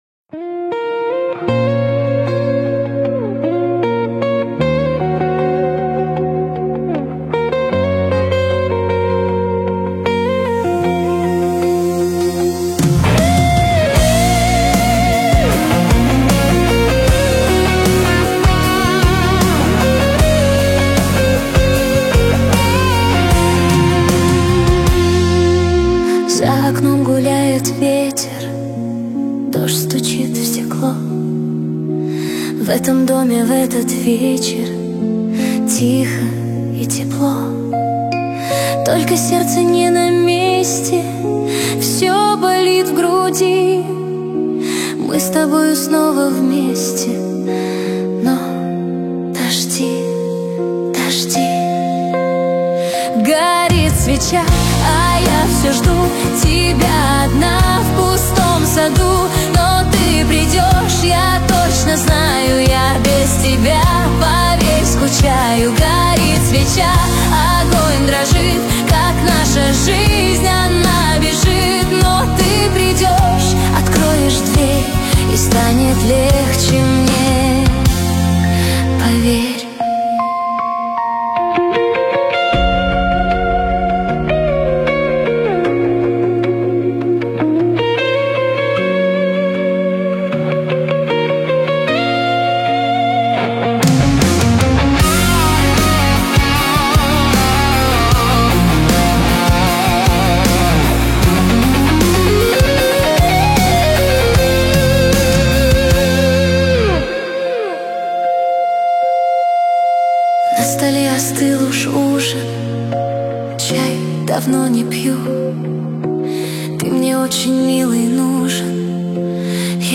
Романтическая песня для души